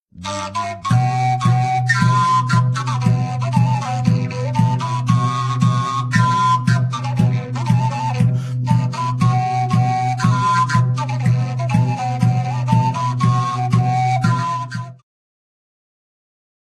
skrzypce
skrzypce, vioara cu guarna
cymbały, altówka, kaval, gordon
wiolonczela
bęben mołdawski